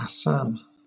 Ääntäminen
Southern England
IPA : /æˈsæm/